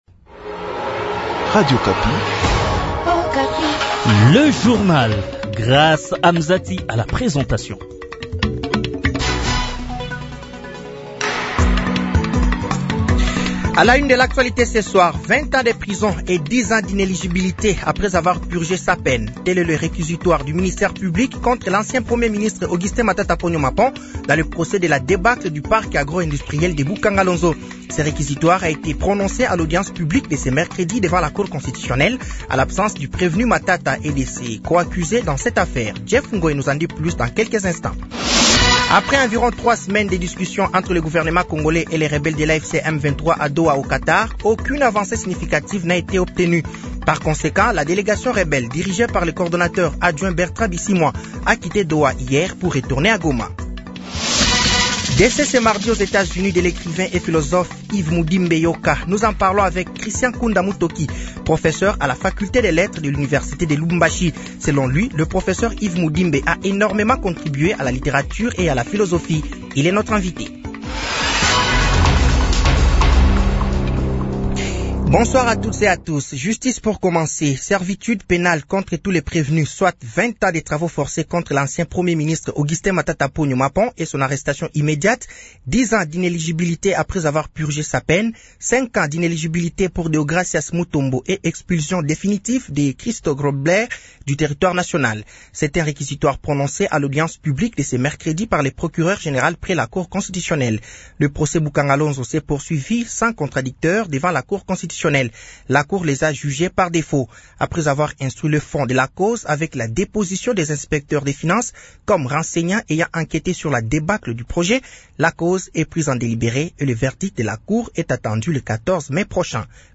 Journal français de 18h de ce mercredi 23 avril 2025